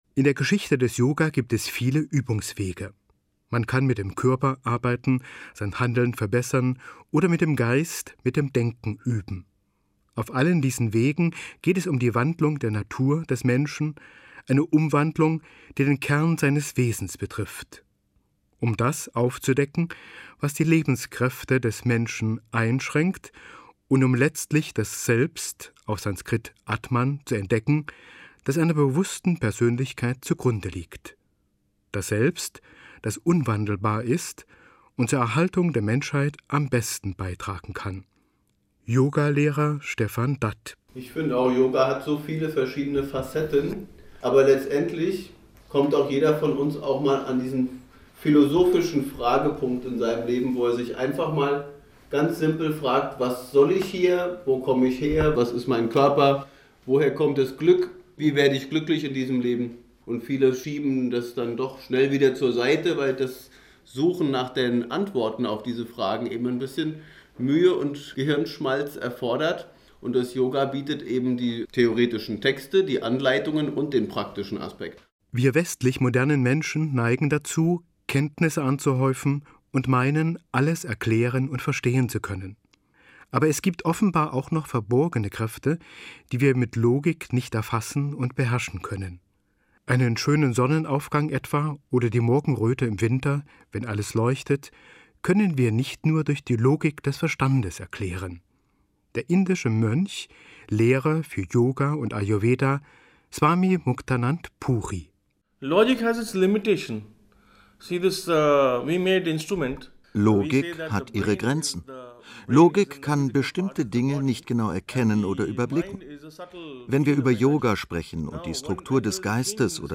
Interview im Deutschlandradio
interview_deutschlandradio.mp3